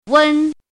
怎么读
wēn yùn yūn
wen1.mp3